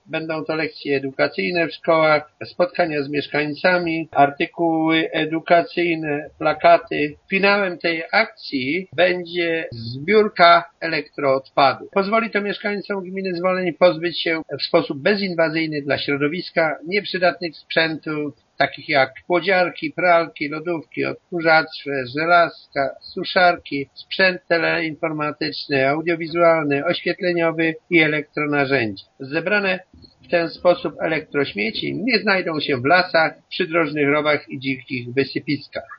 „Ustalono, że w ramach projektu odbędą się spotkania edukacyjne i bezpłatna zbiórka tak zwanych elektrośmieci” – informuje zastępca burmistrza Włodzimierz Kabus: